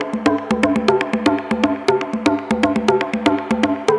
Perc02.mp3